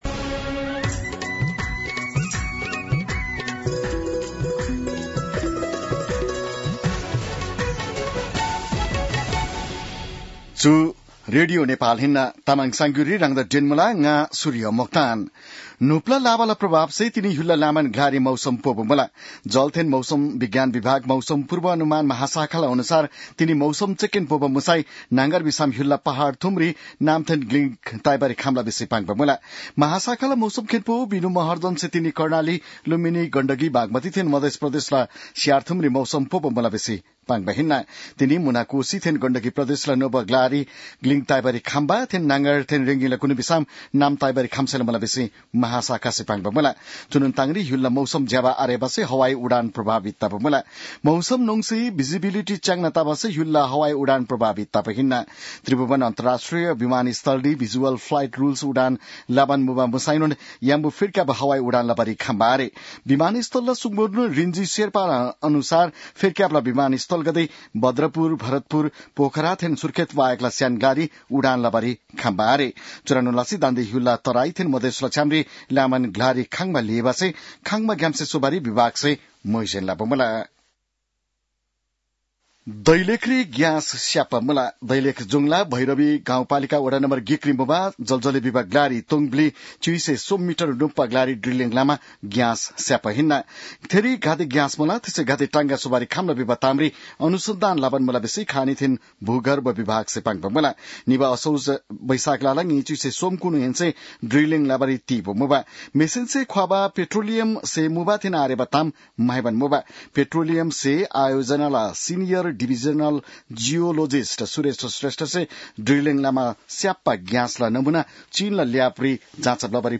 तामाङ भाषाको समाचार : २२ पुष , २०८१